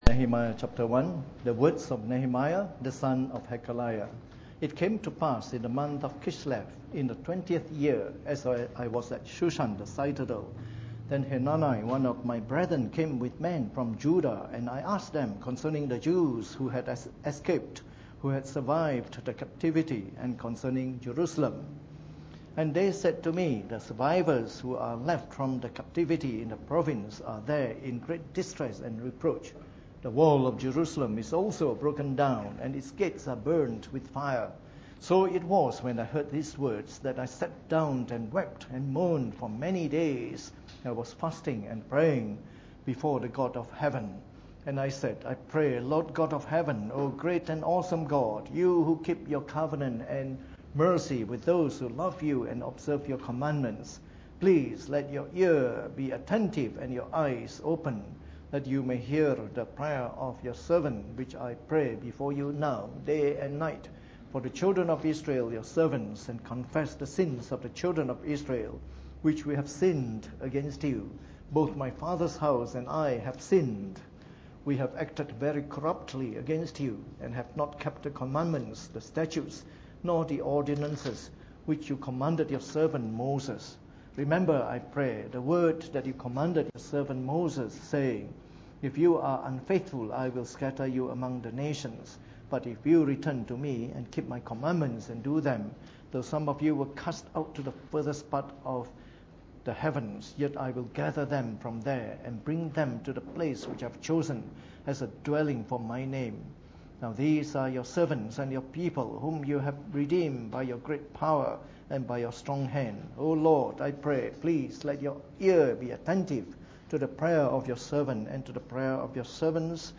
Preached on the 23rd of April 2014 during the Bible Study, from our series of talks on the Book of Nehemiah.